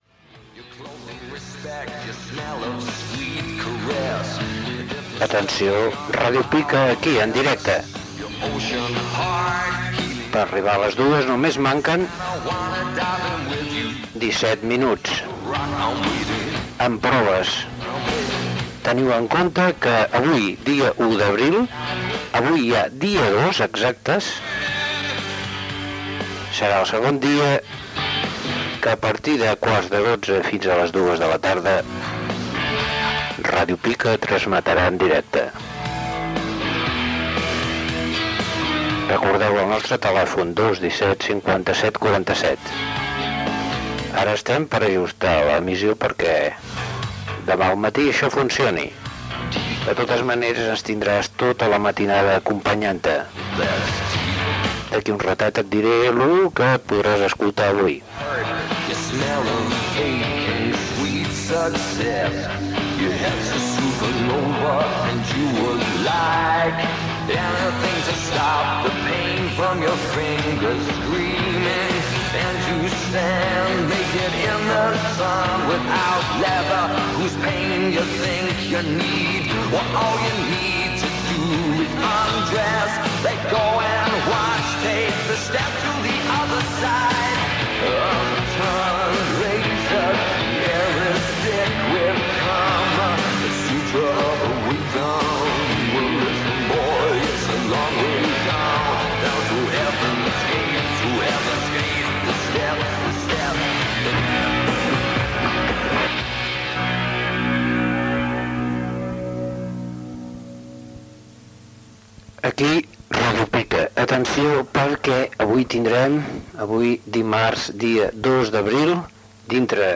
Segon dia d'emissions en directe.
FM